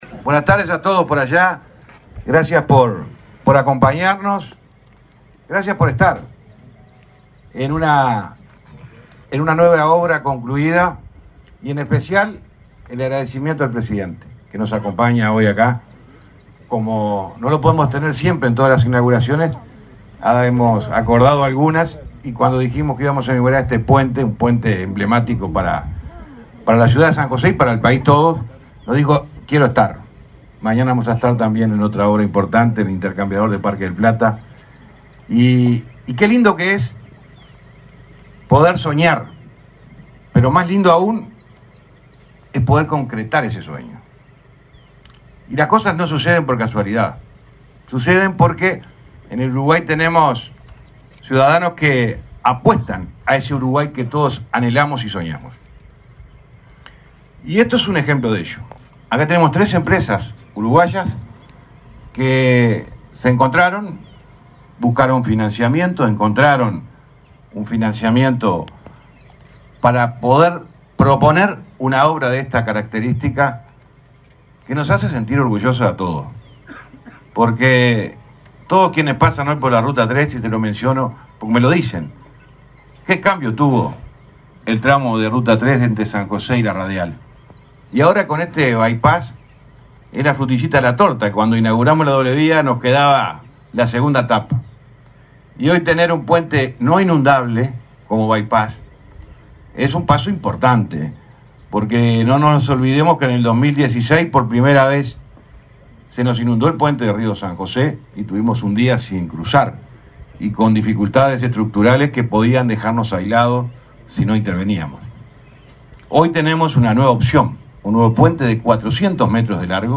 Palabras del titular del MTOP, José Luis Falero
En el evento, titular de la cartera, José Luis Falero realizó declaraciones.